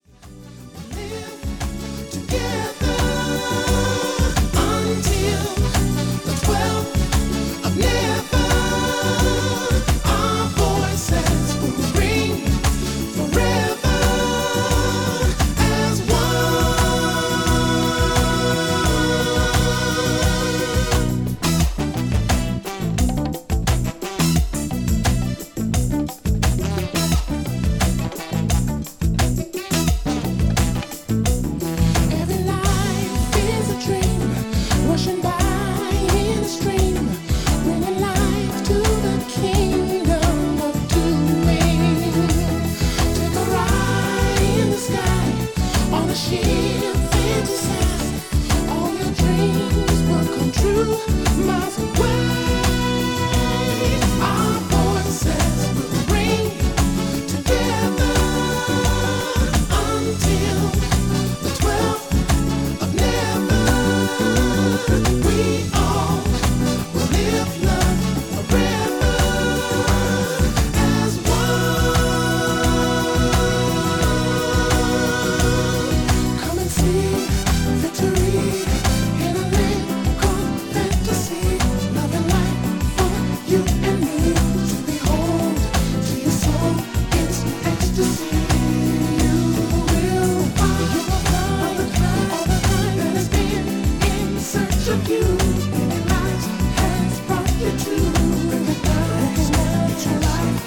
STYLE Soul